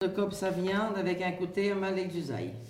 Localisation Sallertaine
Enquête Arexcpo en Vendée
Catégorie Locution